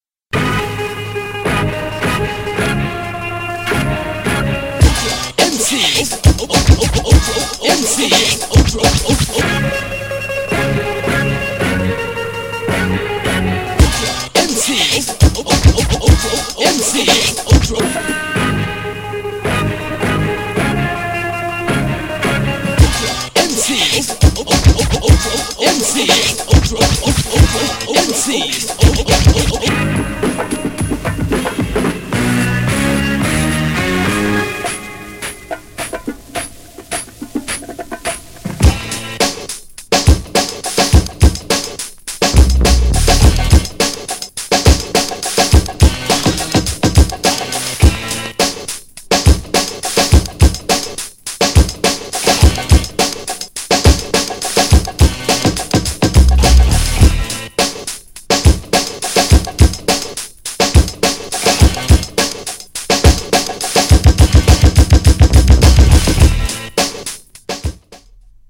重厚なTRACKに息巻くフロウがカッコイイ!! c/wの
GENRE Hip Hop
BPM 111〜115BPM